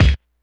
kick05.wav